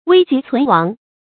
危急存亡 注音： ㄨㄟ ㄐㄧˊ ㄘㄨㄣˊ ㄨㄤˊ 讀音讀法： 意思解釋： 危急：危險而緊急。指關系到生存滅亡的緊急關頭。